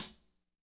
-16  HAT 5-L.wav